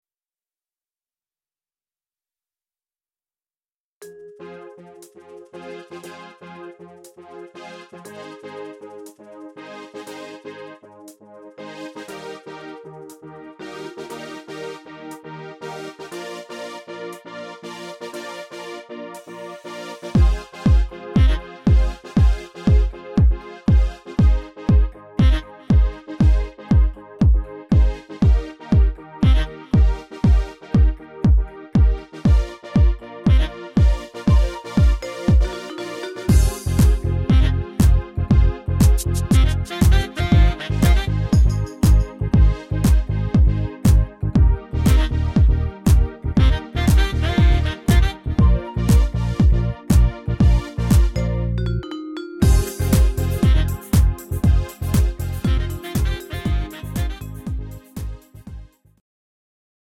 Pop Instrumental